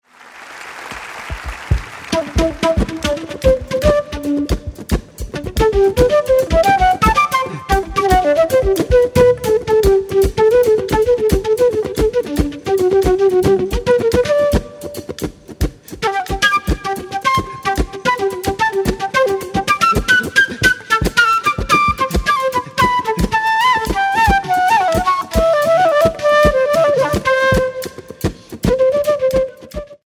Guitarra acústica
Flauta e Sax
Baixo elétrico
Acordeão
Bateria e Percussão